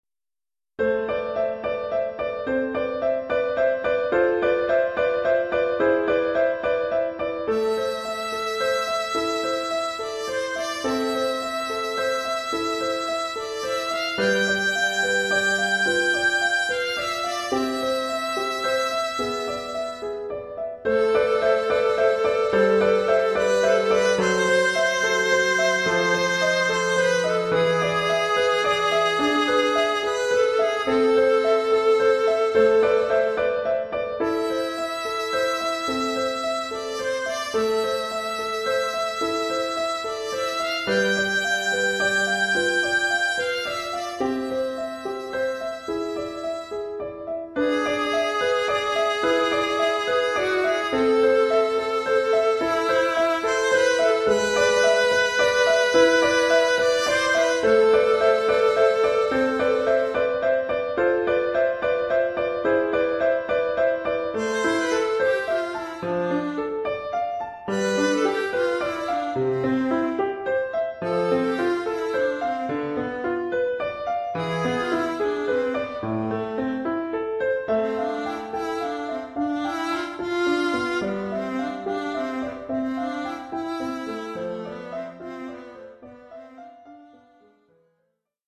1 titre, alto et piano : conducteur et partie d’alto
Oeuvre pour alto et piano.